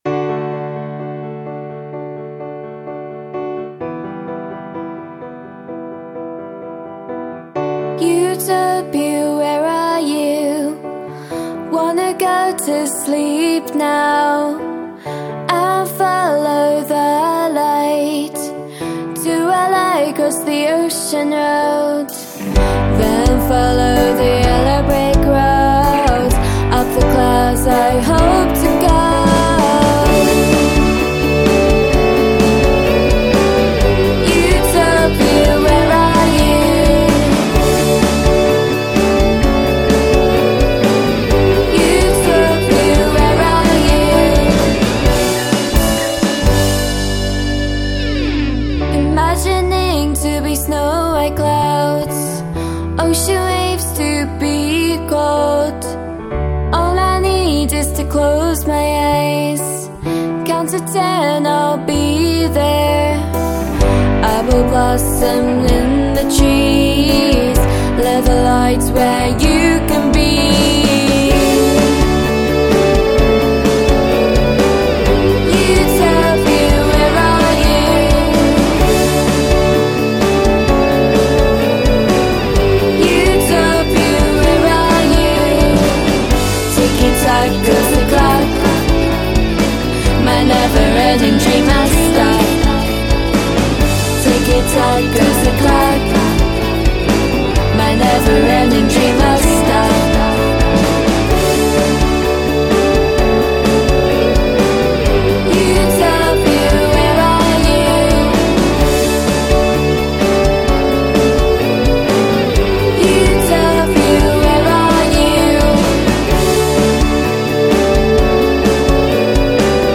This is about someone in a dystopian society searching for their own utopia. As they follow the path towards a light they dream about the place they want to go to. It is an uplifting song to make people feel happy.